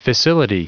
Prononciation du mot fissility en anglais (fichier audio)
Prononciation du mot : fissility